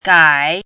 chinese-voice - 汉字语音库
gai3.mp3